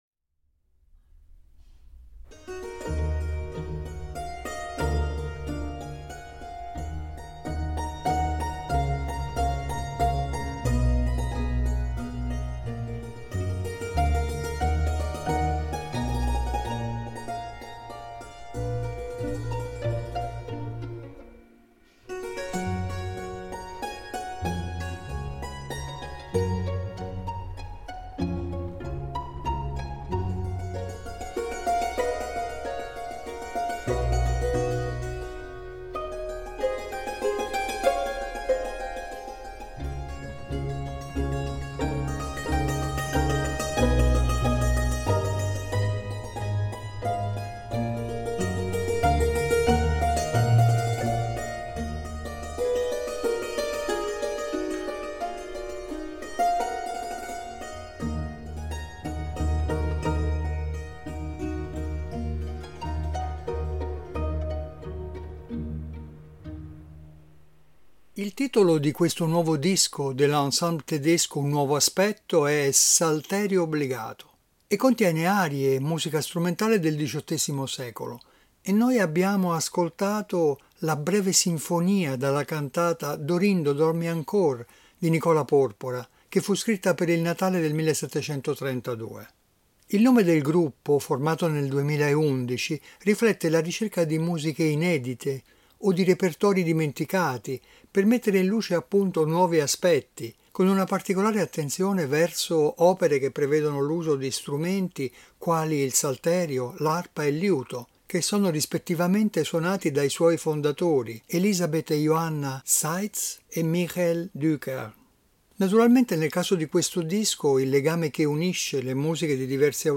La Recensione
Il protagonista di questo nuovo disco è il salterio, utilizzato sia come strumento solista concertante che come accompagnatore di arie d’opera, e nello specifico quello a corde percosse da sottili e leggeri martelletti, il cui suono cristallino sembra rimbalzare sulle armonie prodotte dall’insieme del basso continuo.